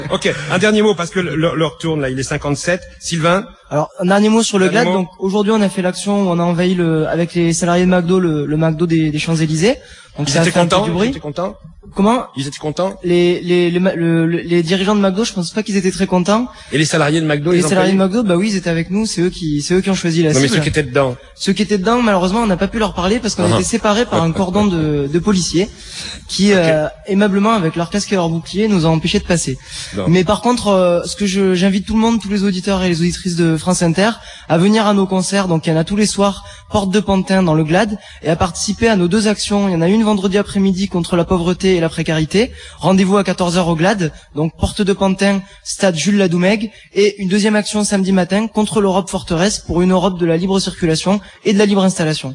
Interventions diffusées le 13 novembre 2003 - dans le cadre du Forum Social Européen en Seine-St-Denis - sur le site de l’émission radiophonique Là Bas si j’y suis.